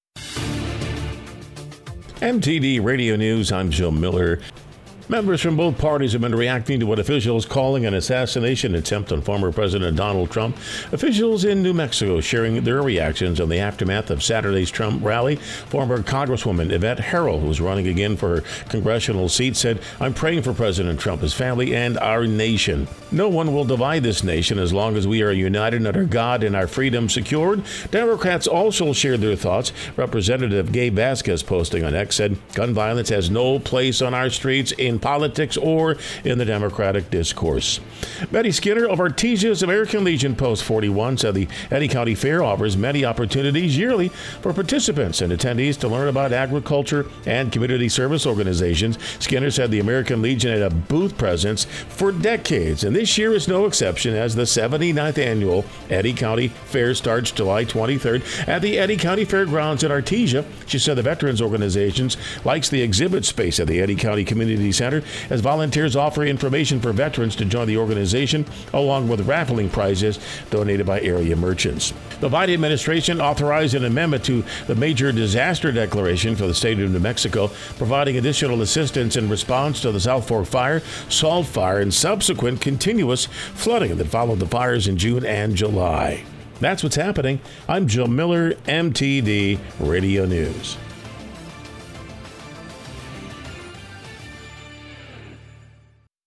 W105 NEWS JULY 16, 2024